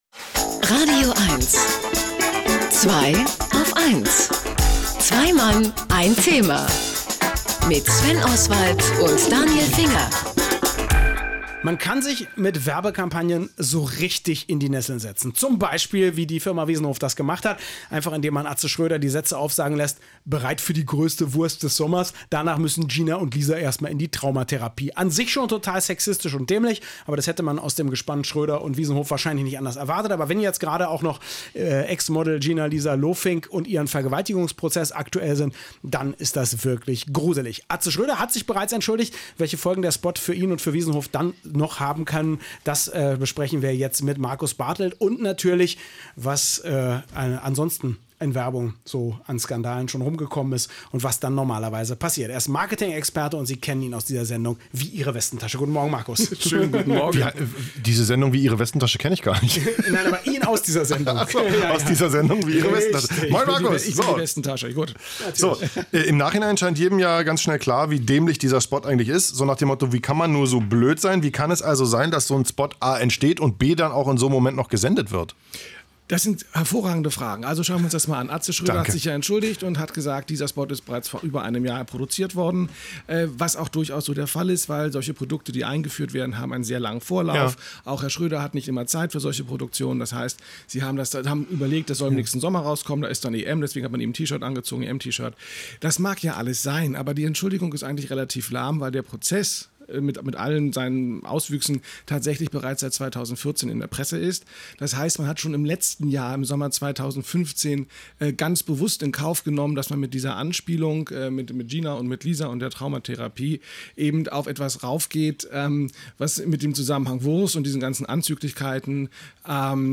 Alles zum Fall Wiesenhof und Atze Schröder aber auch zu Werbeskandalen grundsätzlich wollten die Herren von Zweiaufeins wissen und so luden sie mich – immer wieder sonntags – ins radioeins-Studio ein: